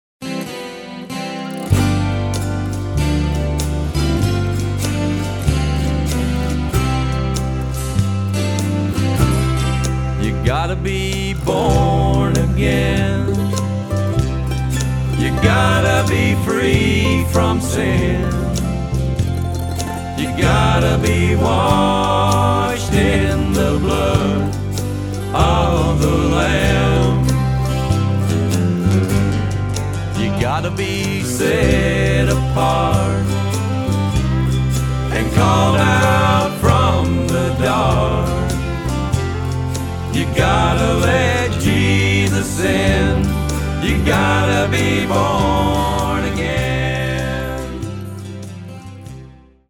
Autoharp, Lead & Harmony Vocals
Guitar
Fiddle